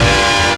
JAZZ STAB 19.wav